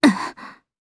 Valance-Vox_Damage_jp_04.wav